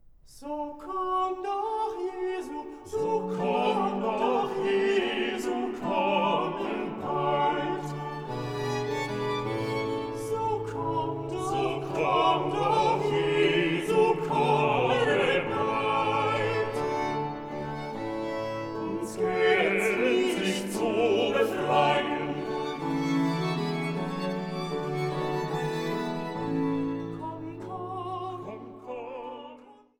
Kantate